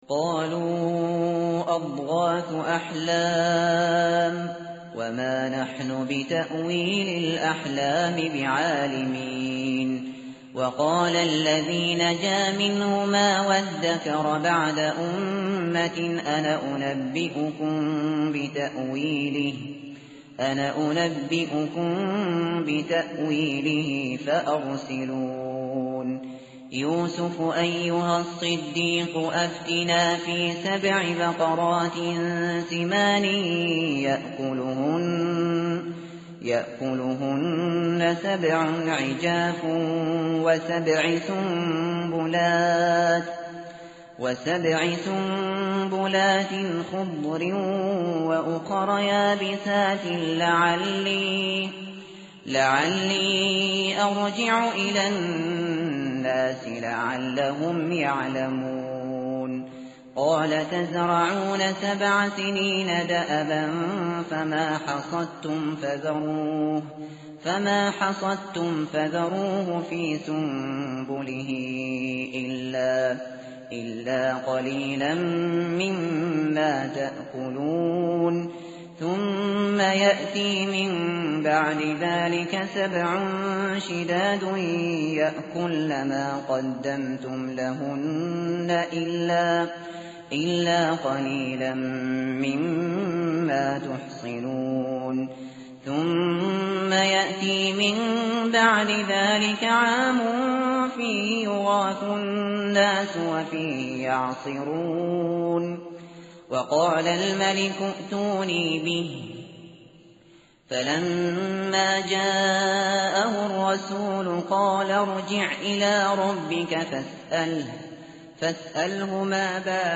tartil_shateri_page_241.mp3